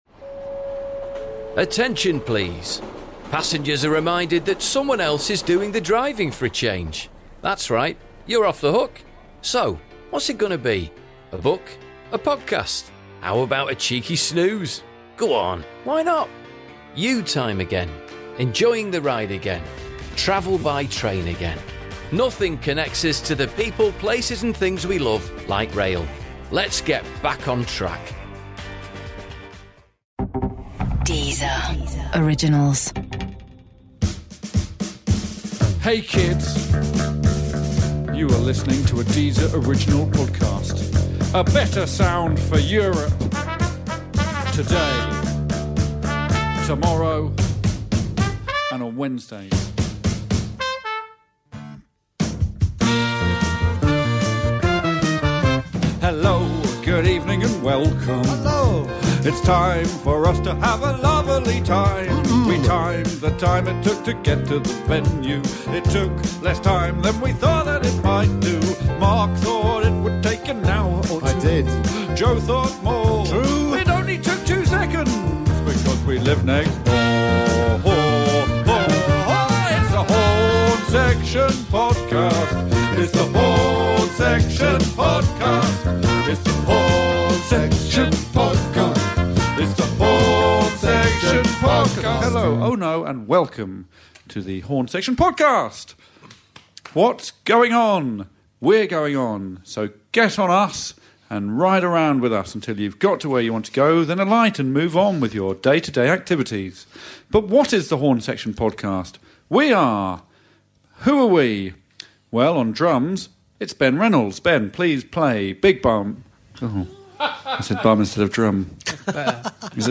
Welcome to The Horne Section Podcast, your new weekly dose of musical nonsense and anarchic chat with Alex Horne and his band! This week we're joined in the studio by actor, poet and comedian Tim Key.